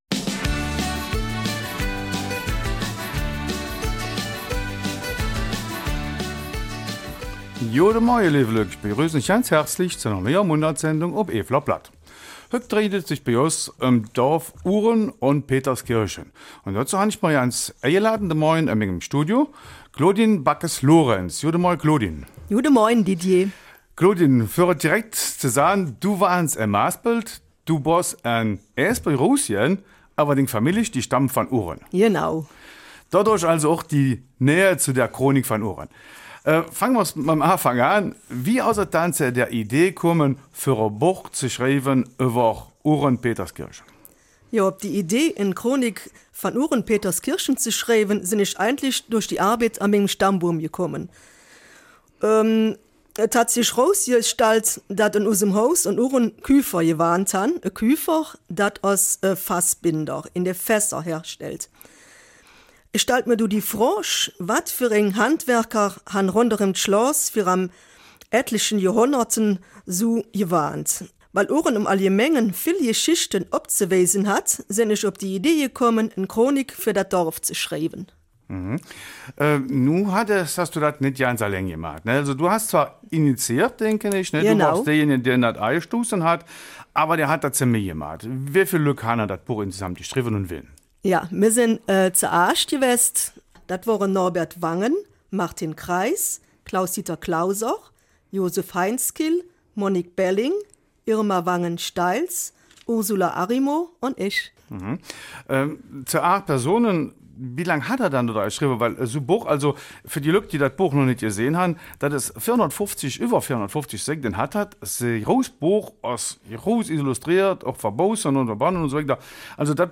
Eifeler Mundart: Buch zur Chronik Ouren-Peterskirchen